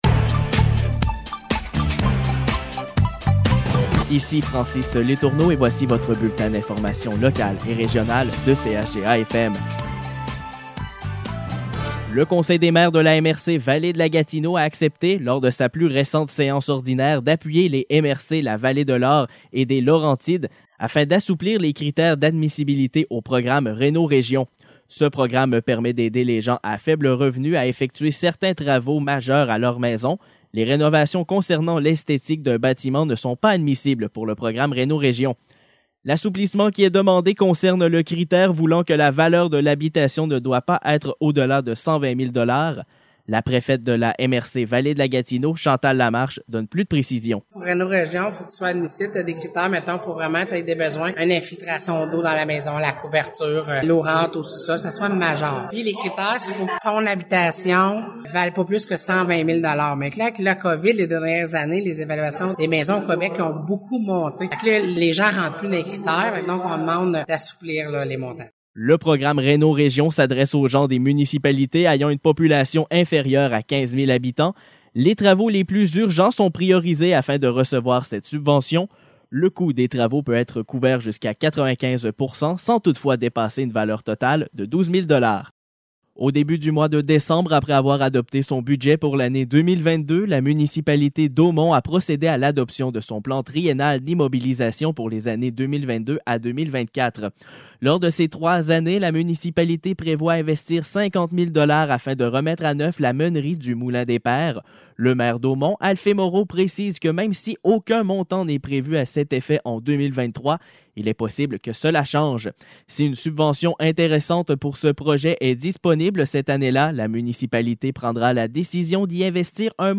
Nouvelles locales - 27 décembre 2021 - 12 h